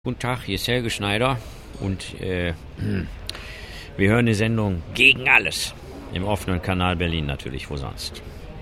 kleinen O-Ton einzusprechen..!
StadionID.mp3